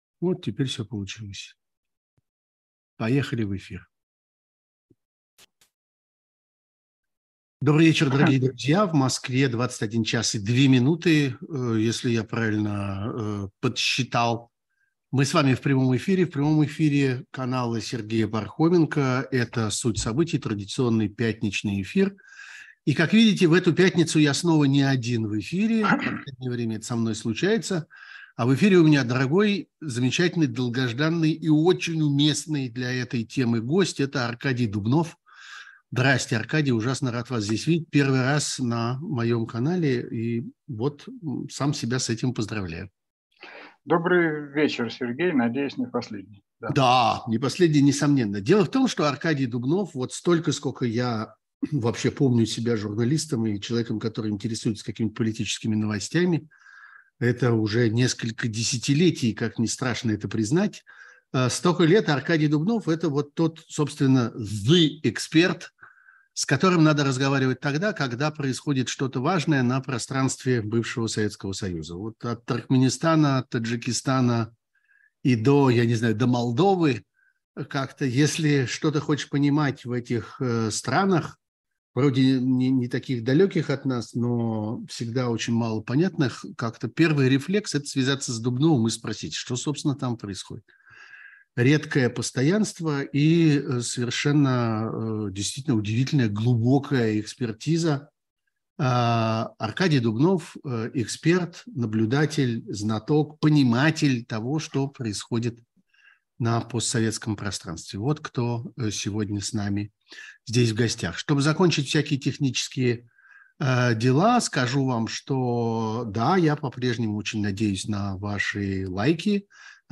Сергей Пархоменко журналист, политический обозреватель
Мы с вами в прямом эфире канала Сергея Пархоменко.